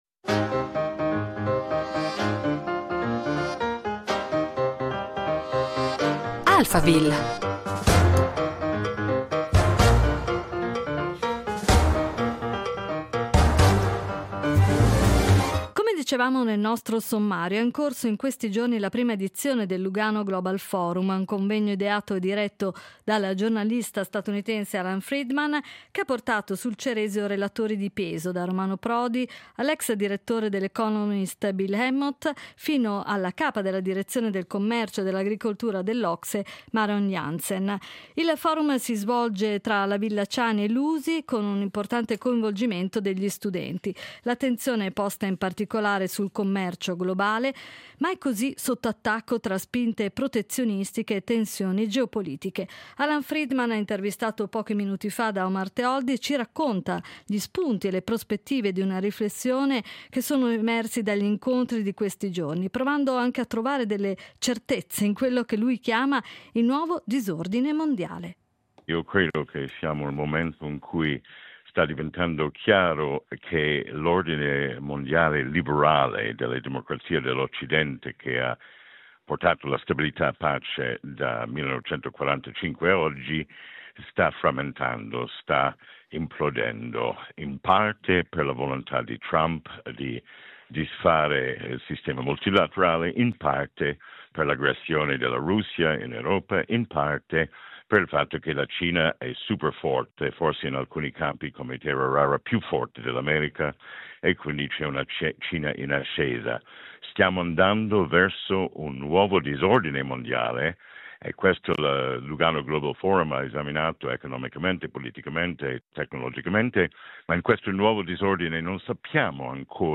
Il focus è stato posto sul commercio globale, mai così sotto attacco tra spinte protezionistiche e tensioni geopolitiche. Alan Friedman ci racconta gli spunti e le prospettive di riflessione emersi dagli incontri di questi giorni, provando a trovare delle certezze in quello che lui chiama il “Nuovo Disordine Mondiale”.